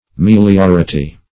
Search Result for " meliority" : The Collaborative International Dictionary of English v.0.48: Meliority \Mel*ior"i*ty\, n. [LL. melioritas, fr. L. melior.